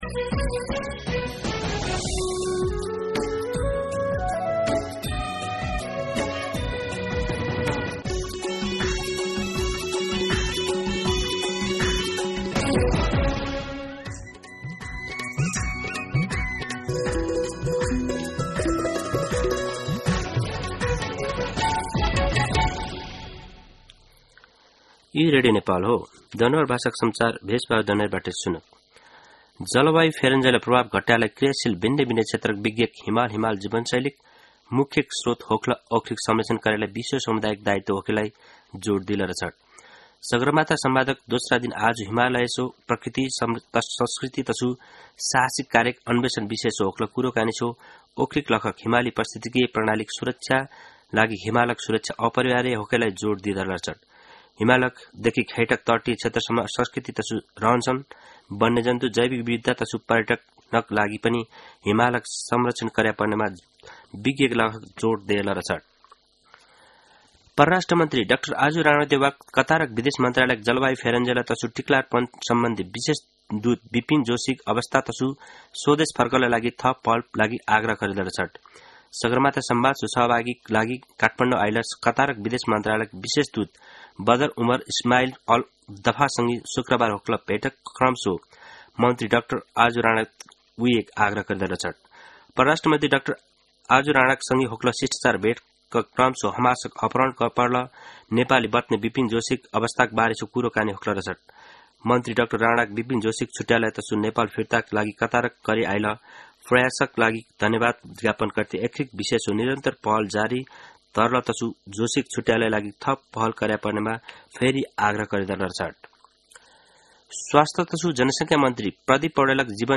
दनुवार भाषामा समाचार : ३ जेठ , २०८२
Danuwar-News-3.mp3